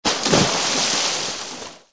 AV_jump_in_water.ogg